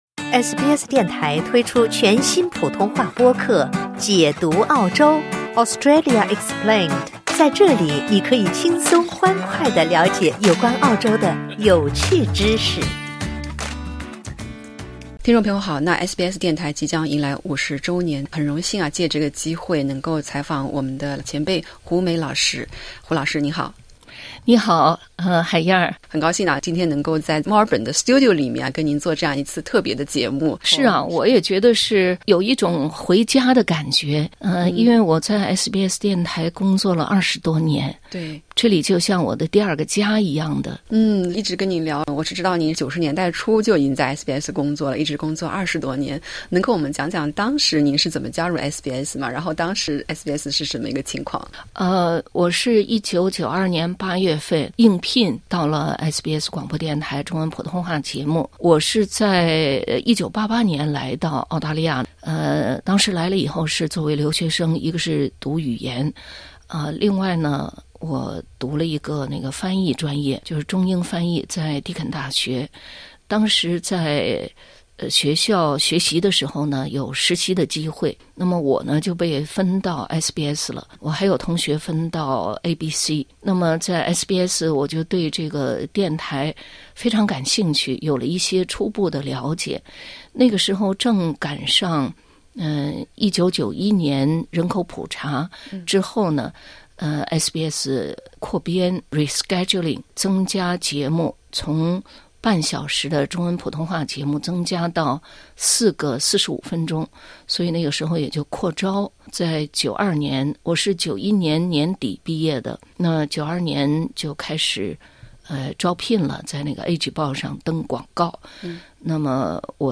在SBS墨尔本演播室录制采访